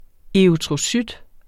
Udtale [ eʁytʁoˈsyd ]